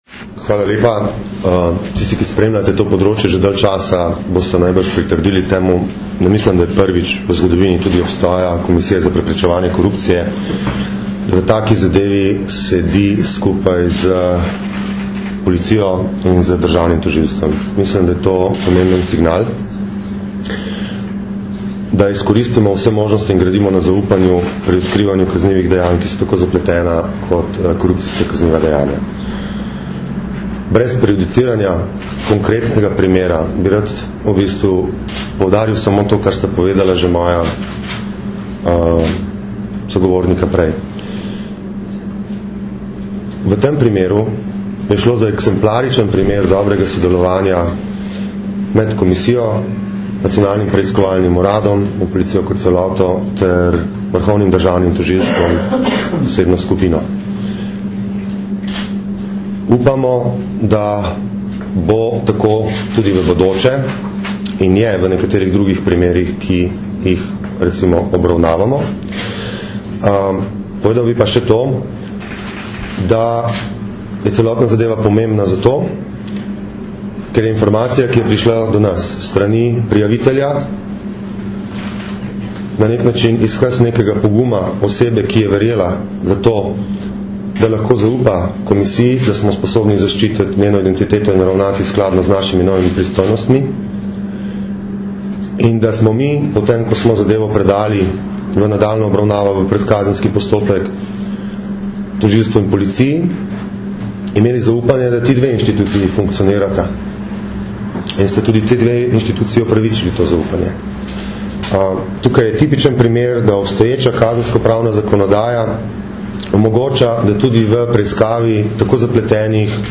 Zvočni posnetek izjave mag. Gorana Klemenčiča, predsednika Komisije za preprečevanje korupcije (mp3)